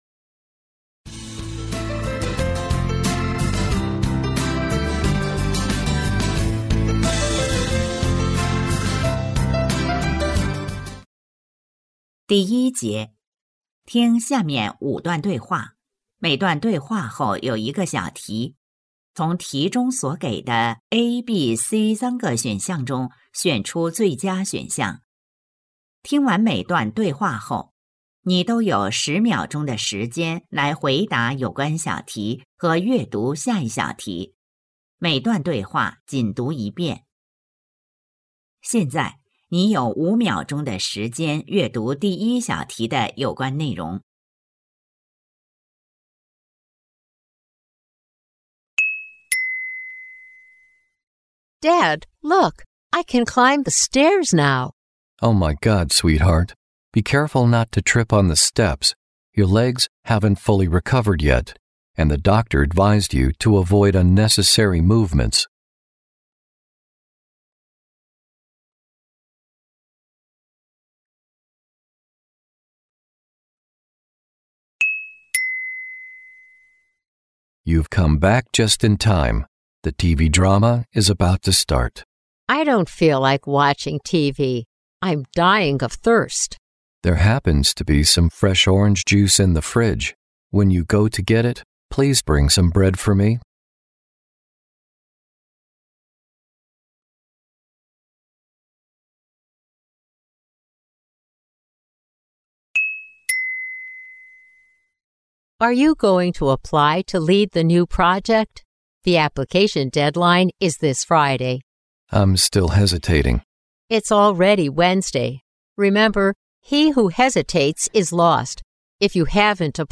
成都树德中学2025-2026学年高三上学期10月月考英语听力.mp3